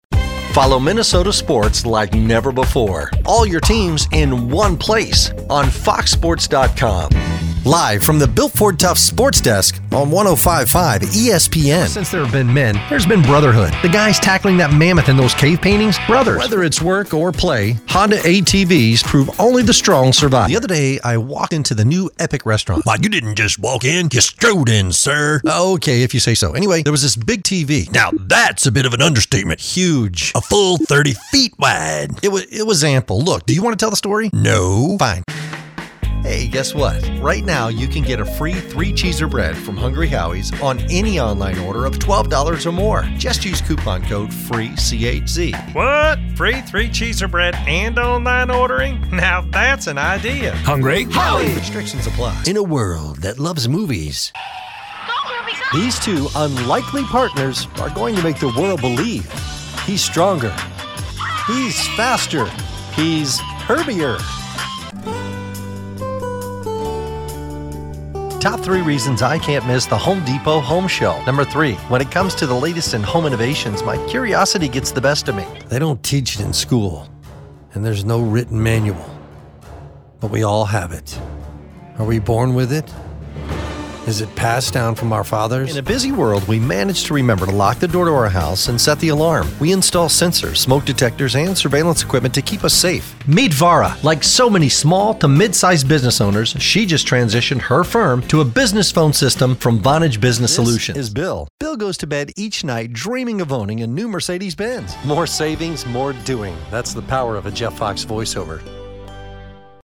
Friendly, Warm, Conversational.
Commercial